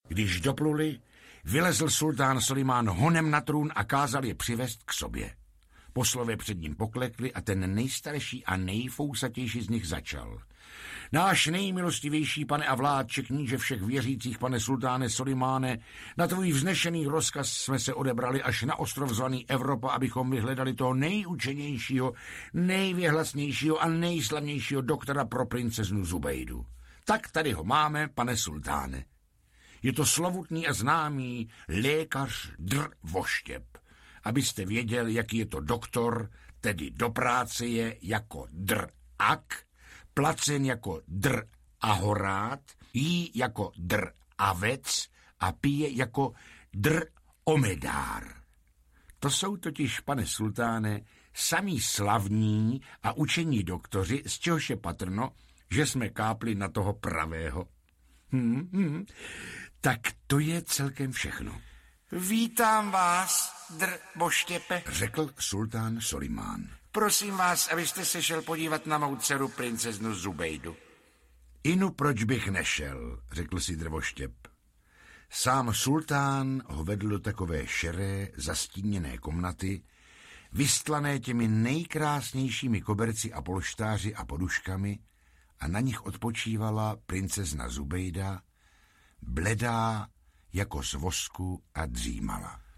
Devatero pohádek 1 audiokniha
Ukázka z knihy